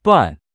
Duan4 duàn male audio wrong
The male audio for duan4 sounds like it’s saying a completely different sound, like ‘bai’ or ‘butt’.
FWIW, I asked a few friends to guess what was being said and they heard “butt,” “bun,” and “ban” but no one said duan.